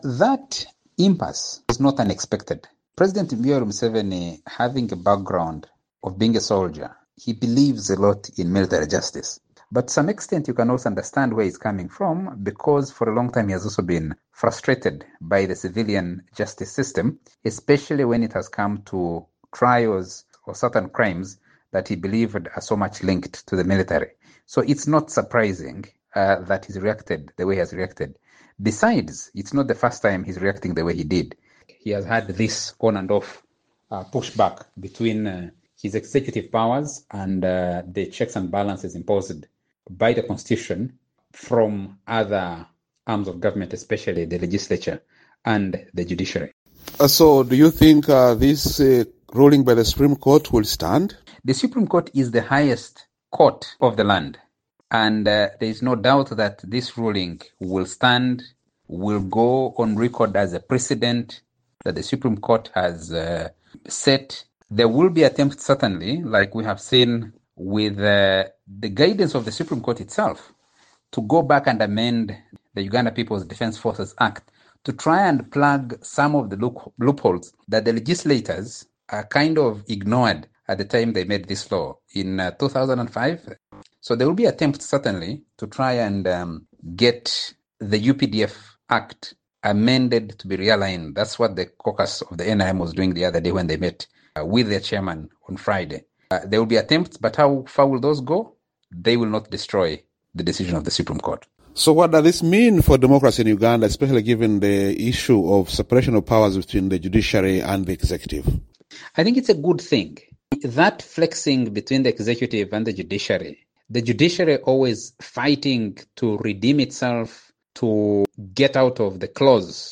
Despite Uganda’s Supreme Court’s ruling that trying civilians in military courts is unconstitutional, there has been no action yet to implement the court’s decision. Ugandan President Yoweri Museveni criticized the ruling, saying the country is not governed by judges but by the people. Political analyst